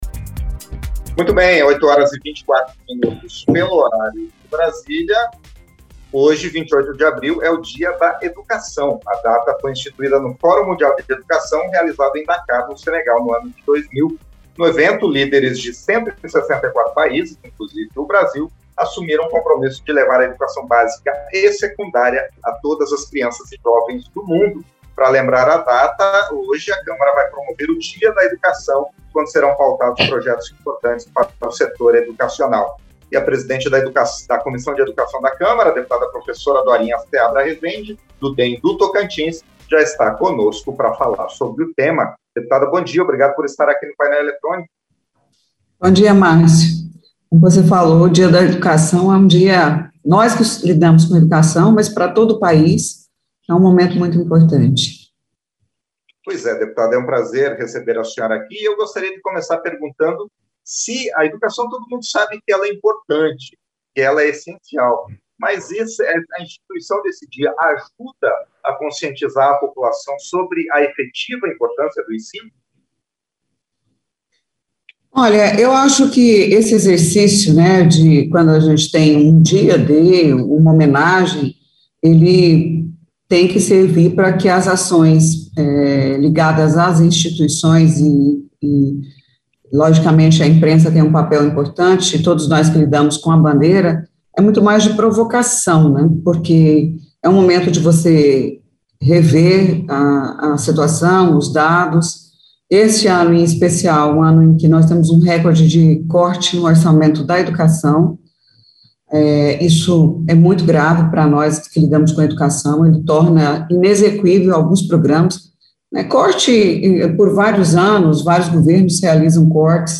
Entrevista - Dep. Professora Dorinha Seabra Rezende (DEM-TO)